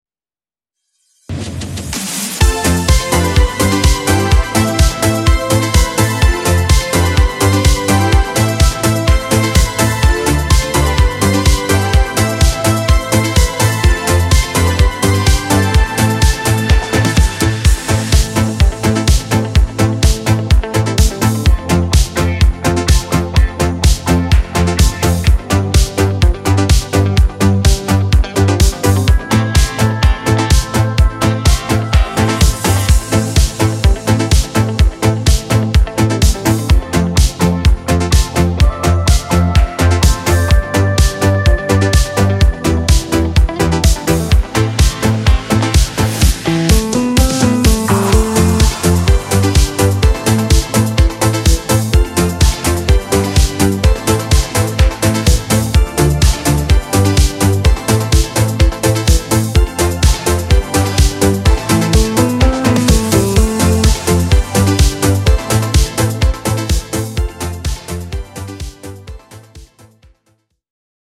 Wielki przebój nurtu Italo Disco